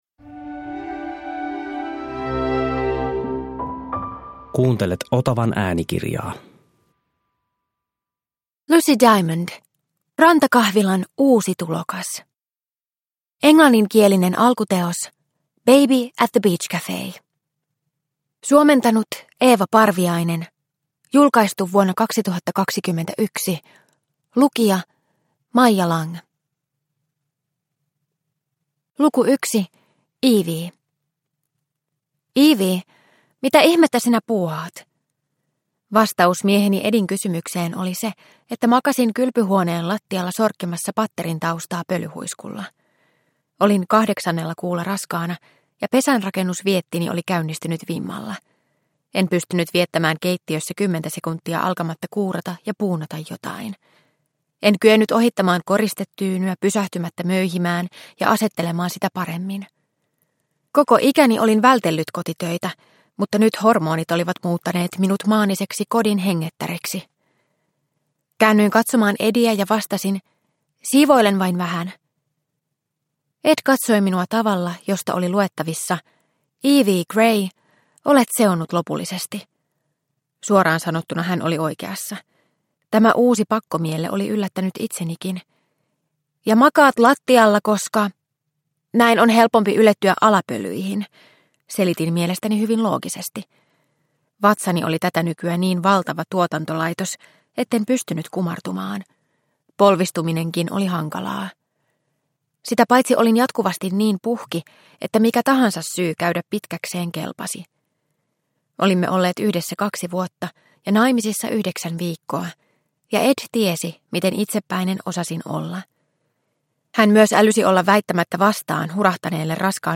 Rantakahvilan uusi tulokas – Ljudbok – Laddas ner